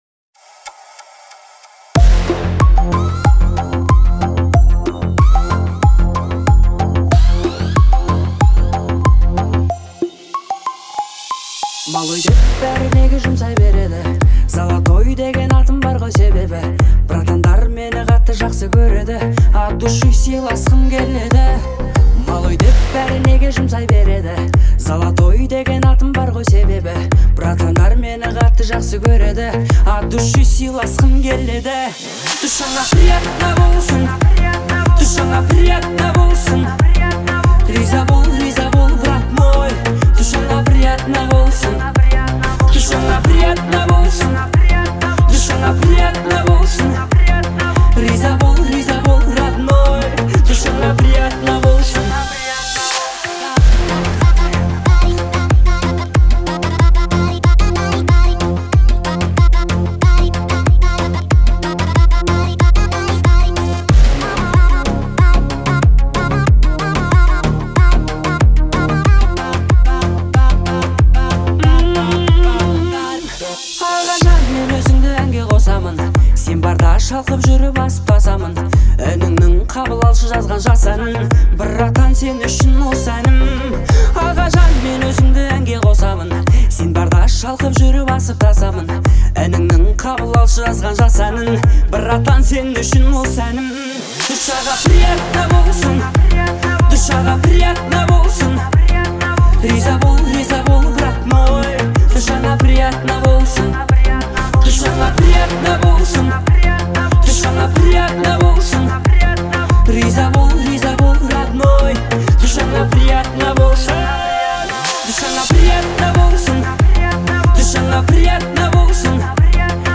это трек в жанре современного казахского поп